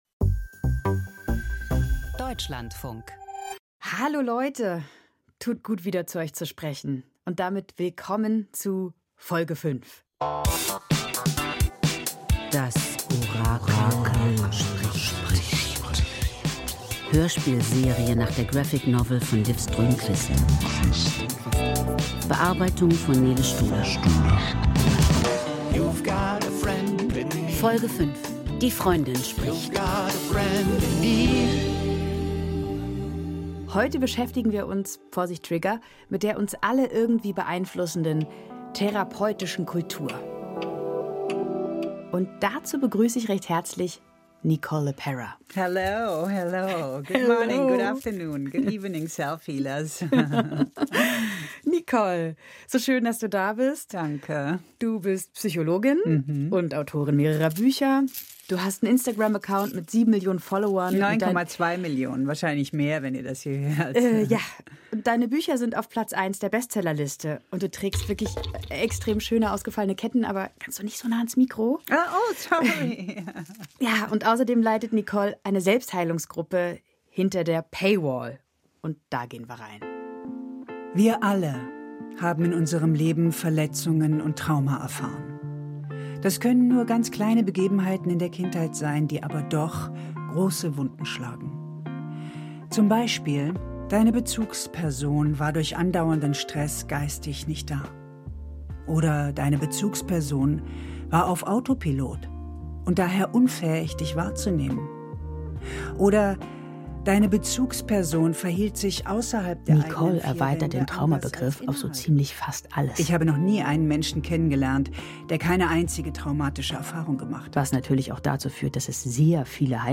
Aus dem Podcast Hörspiel Podcast abonnieren Podcast hören Podcast Hörspiel Klassiker der Literatur, spannende Dramen, unterhaltende Erzählungen und innovative Sound Art....